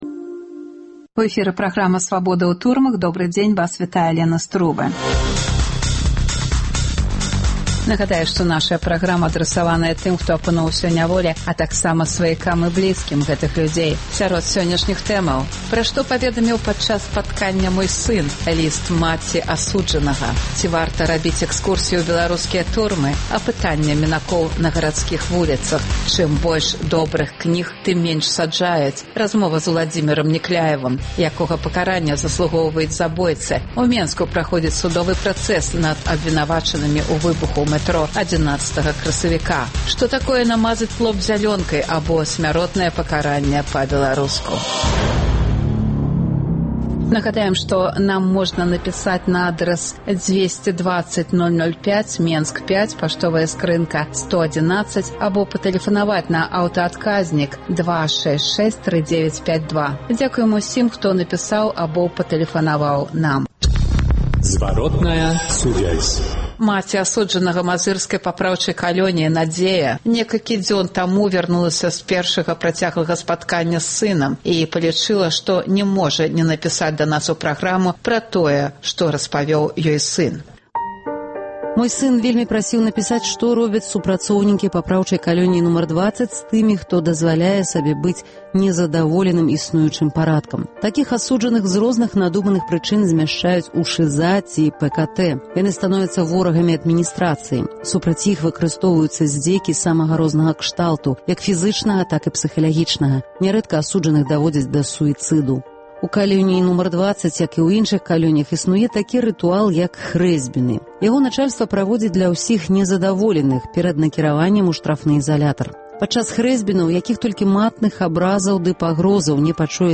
Апытаньне мінакоў на гарадзкіх вуліцах " Уся беларуская літаратура прайшла праз "амэрыканку" Гутарка з Уладзімерам Някляевым Што азначае “мазаць лоб зялёнкай”?